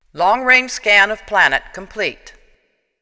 LCARS computer
computer.wav